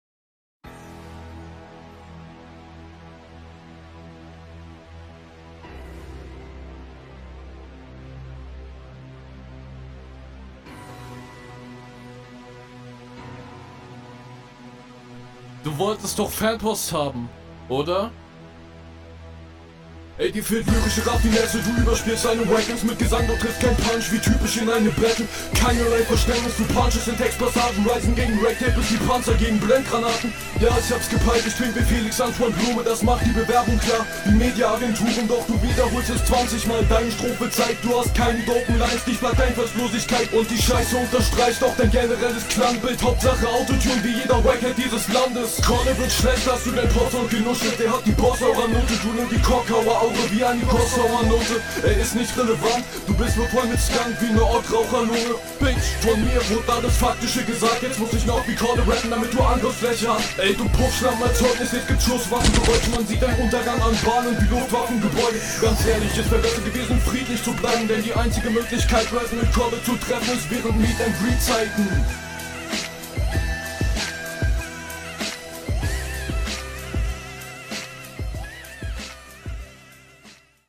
OK der fanpost beat.